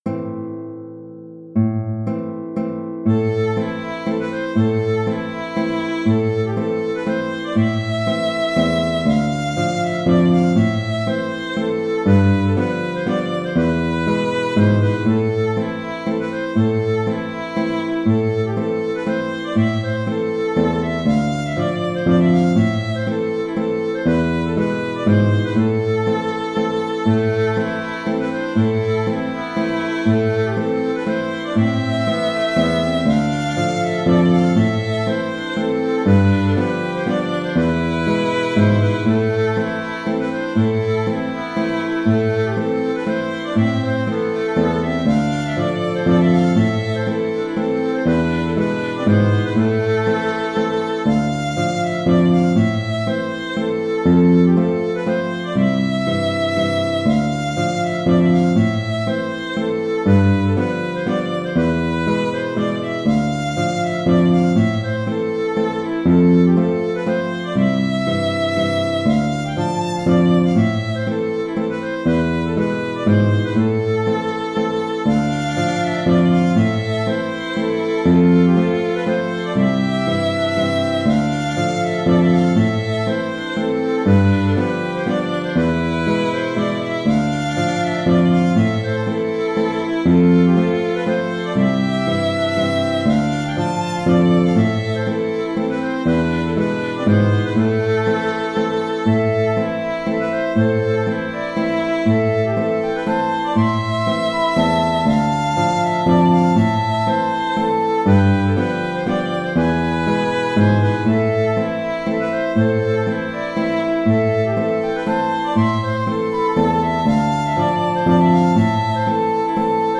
Mazurka Auteur
Valse lente ou mazurka
Le contrechant écrit est repris ensuite.